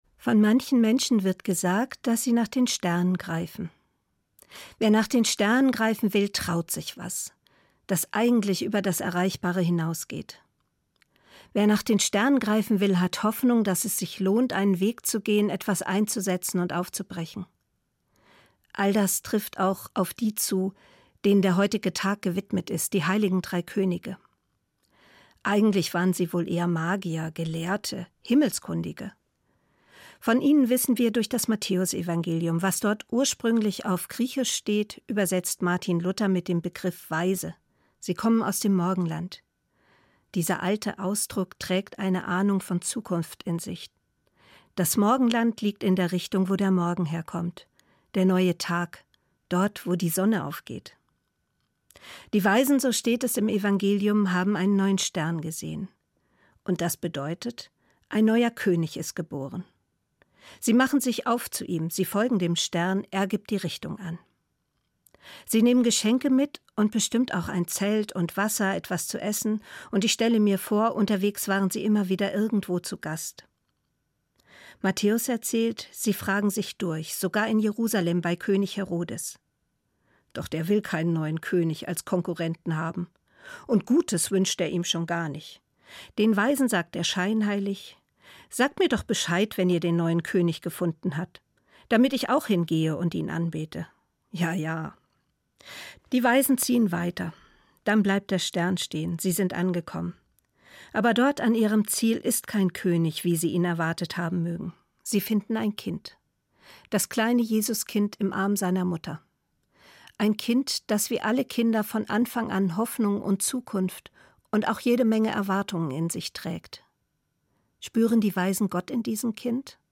Evangelische Pfarrerin, Bad Homburg